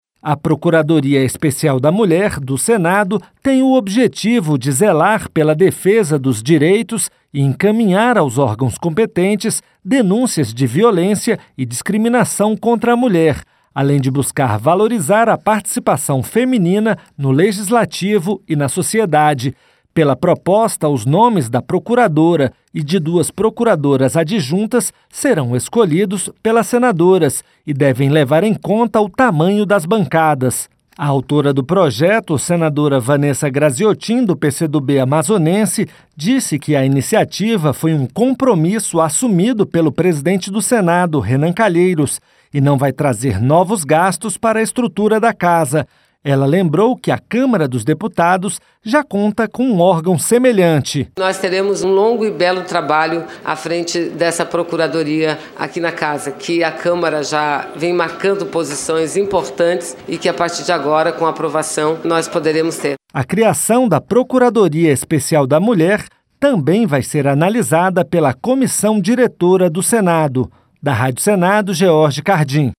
Rádio Senado